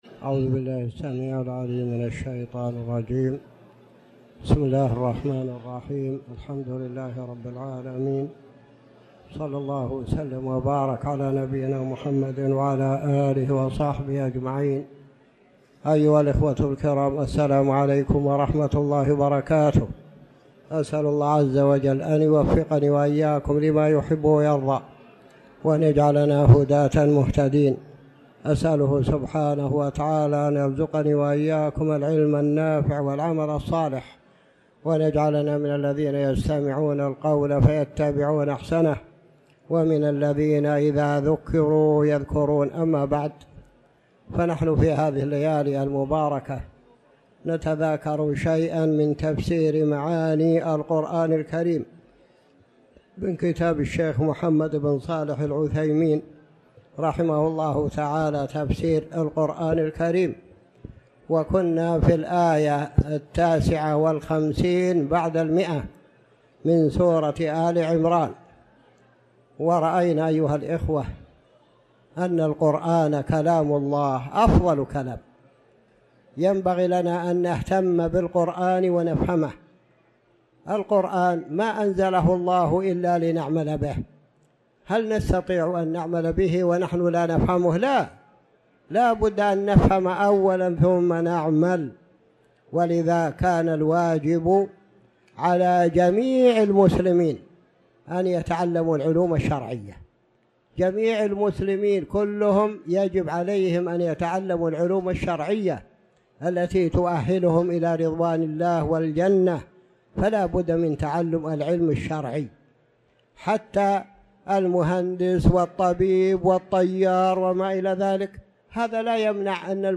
تاريخ النشر ٤ ربيع الأول ١٤٤٠ هـ المكان: المسجد الحرام الشيخ